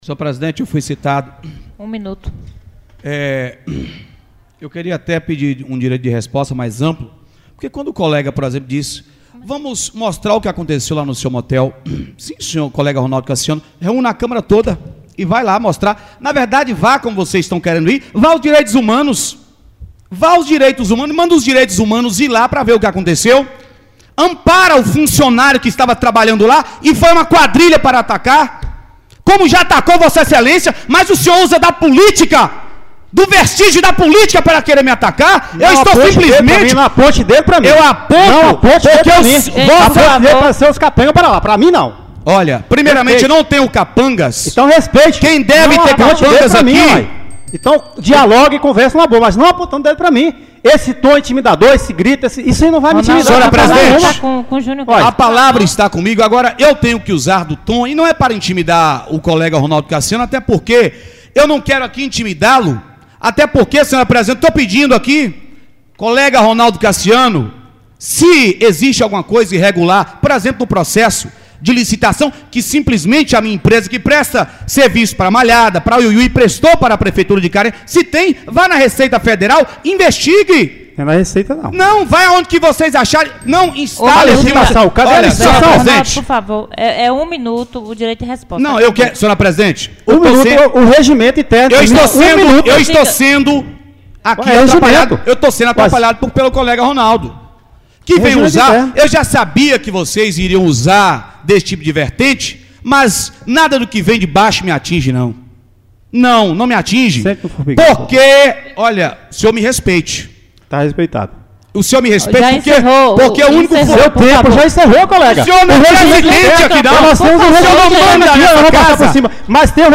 A sessão só não tomou rumos diferentes por que a presidente da Casa cortou os microfones dos dois.
Clique para ouvir a discussão dos dois vereadores
Discursão-dos-dois-vereadores.mp3